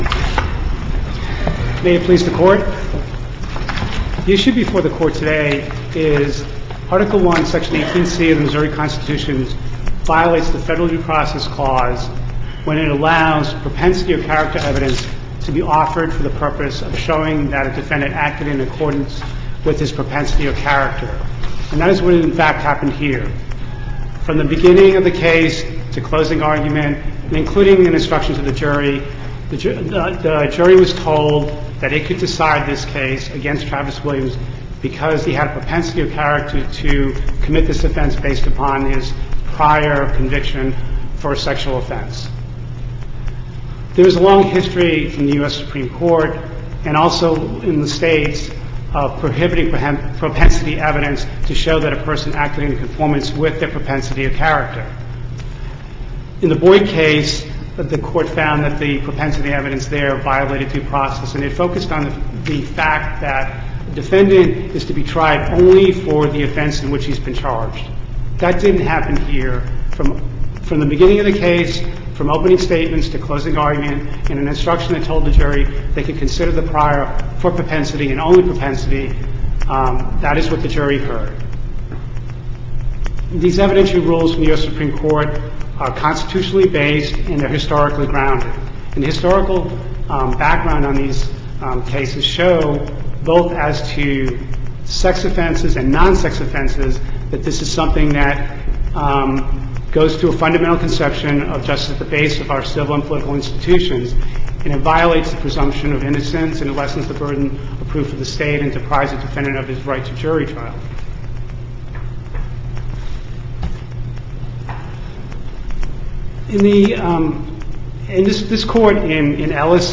MP3 audio file of arguments in SC96107